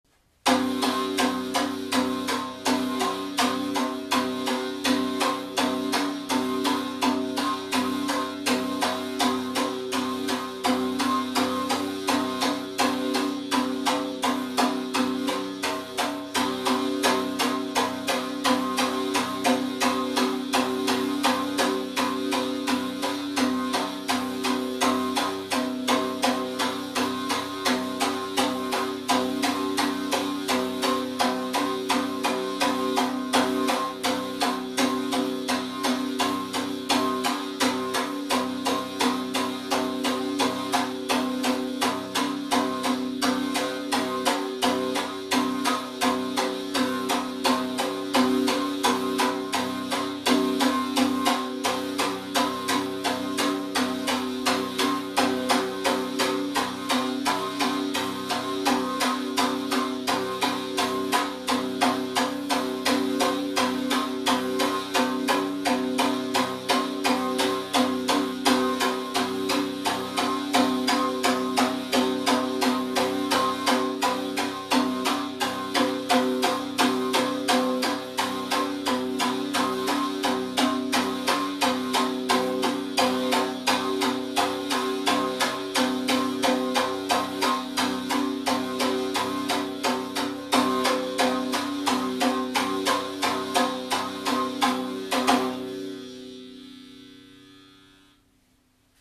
Ttun-ttun kadentzia dantza-jauzien aldairetarako